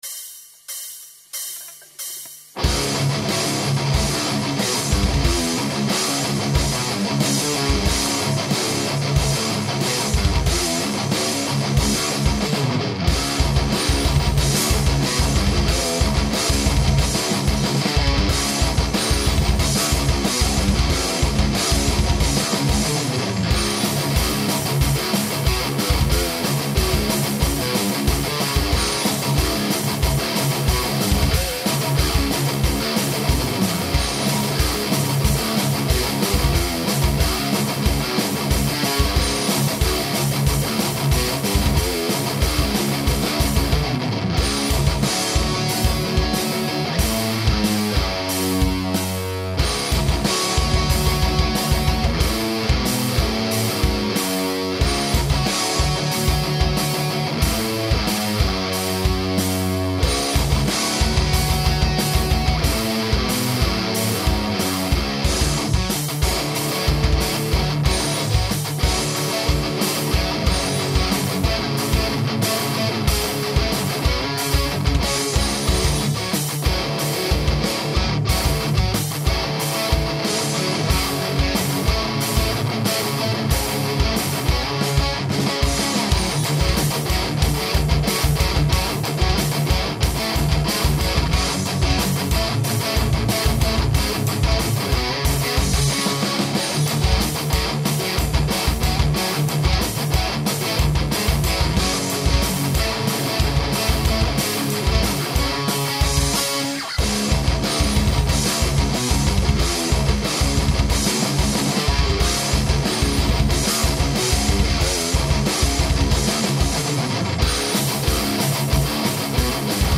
takze tu je ukazka vht, zvuk vsak nie je az tak dobry, to je mojou snahou o mixaz :) darmo kto vie ten vie, kto nie....
je to s 2x12 boxom homemade, sennheiser e606 mikro
to VHT ma strašne tipicky zvuk...pekna tema akurat ju treba rytmicky vybrusit...ale palec hore...
v tej ukazke chyba basa, tak je to trochu vykastrovane, dorobim neskor
musi byt radost hrat na taky aparat. ide z toho zvuku velka charizma Bravooo
yo man, that´s the modern matafaka higain...
Je to cele tak o polovicu tichsie, ako je sucastny standard, kopak aj rytmicak nevyrazny :) Tie bicie zneju, ako keby boli "tocene" len na overheady. Gitara super.
bicie su len sample, len podmaz, cez battery 3